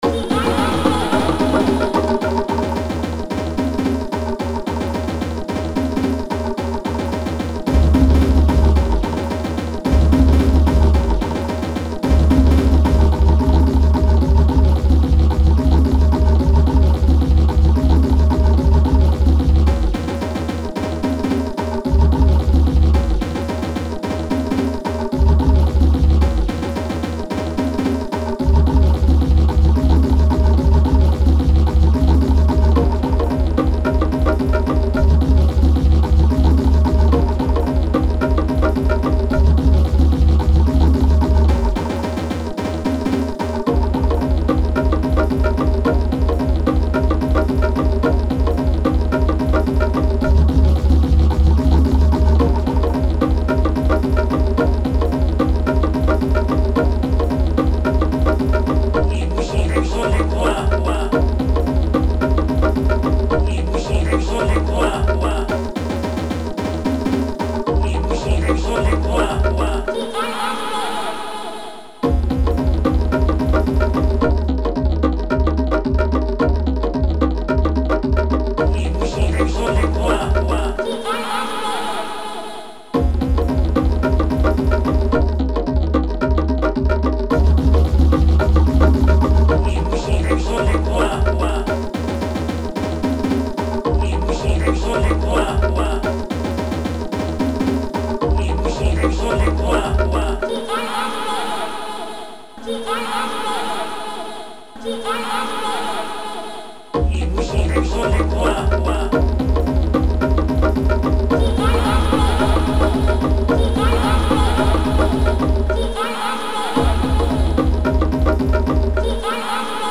salut a vous tous voila je me suis mi sur fruityloops et j ai pondu ce petit morceau de 6mn et j aimerai avoir votre avis :?:
Pour ma part je trouve, et ça ne concerne que moi, que tu souffre du syndrome "je débute sur Fruity" qui signifie : bcp de samples et de répétitions.
Il ya de l'idée mais ça se répète bcp bcp bcp.